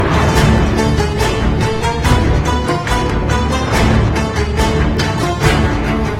en bonus deux pistes sonores initialement prévue pour les sonneries d'intercours : une musique classique d'un compositeur célèbre du pays et une son plus "inattendu".